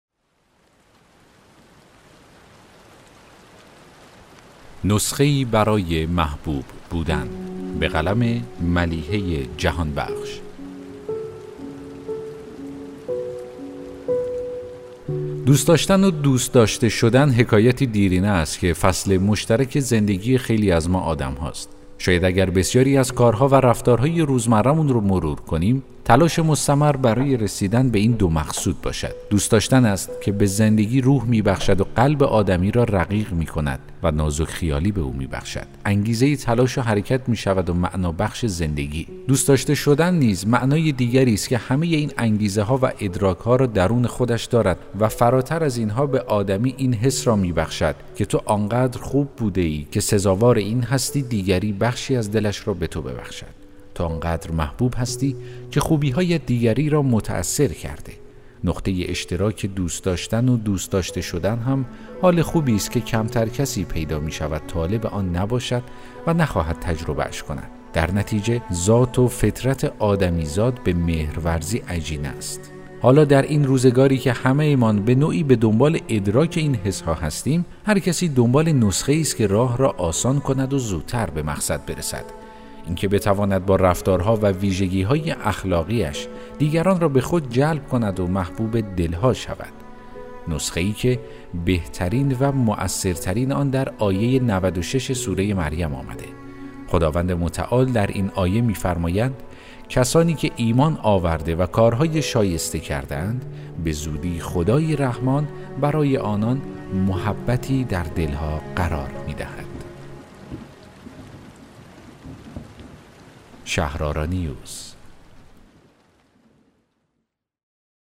داستان صوتی: نسخه‌ای برای محبوب بودن